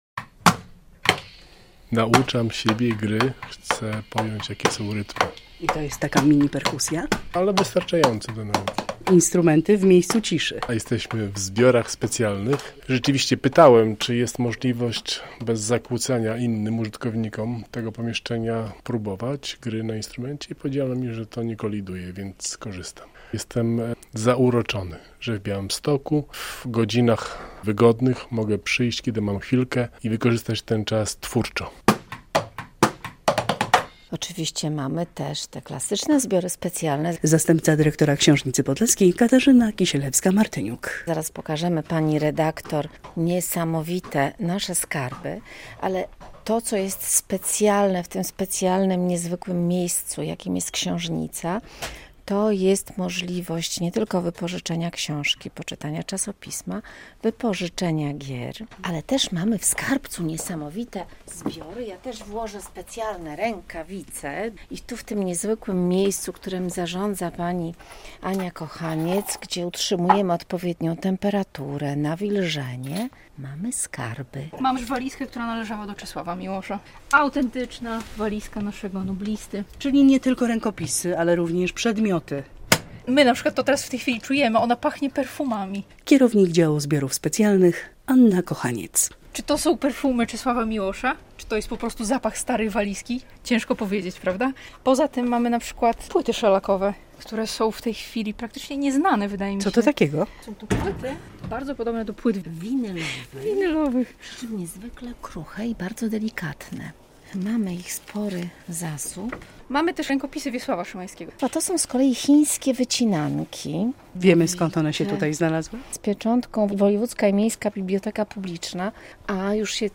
Dział Zbiorów Specjalnych w Książnicy Podlaskiej - relacja